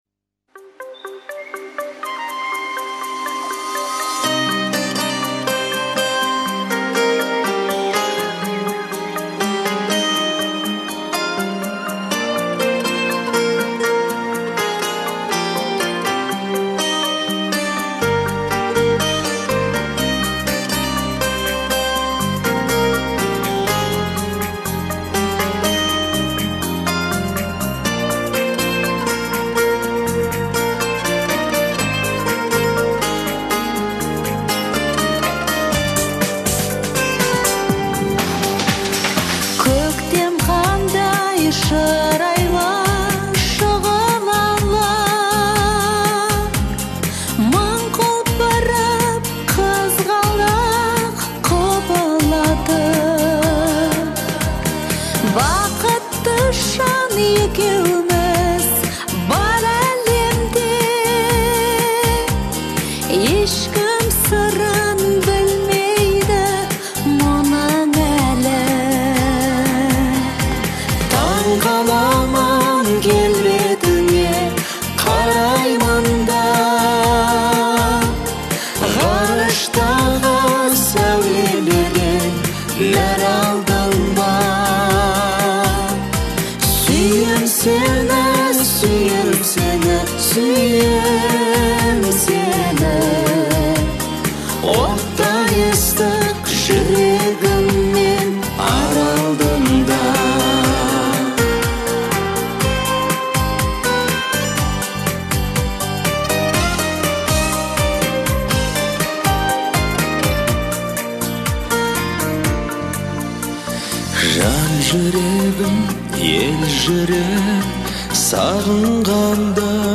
это романтическая песня в жанре казахского попа